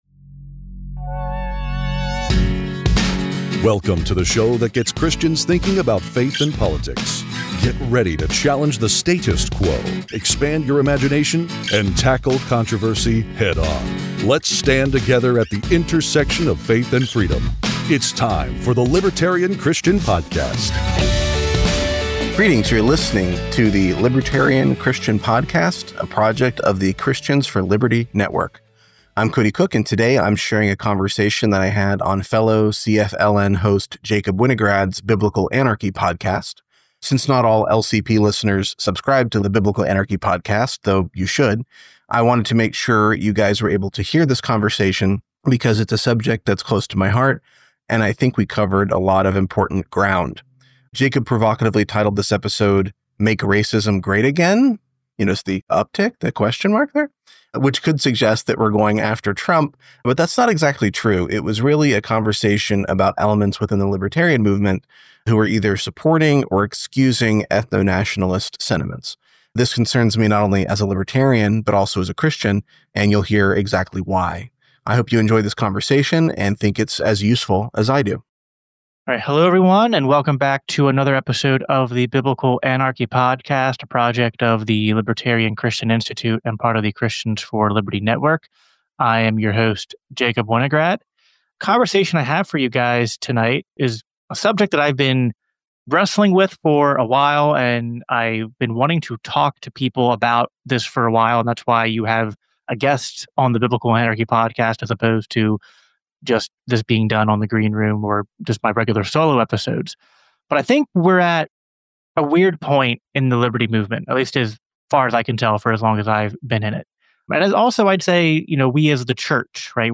This is a cleaned-up version of what we thought was a really useful unedited conversation on the Biblical Anarchy Podcast.